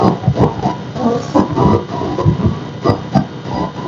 Â I used the induction loop receiver to listen in to the sound of my computer.
Â I recorded this not through a microphone but an induction loop receiver that monitors the electromagnetic field emanating from the electronics.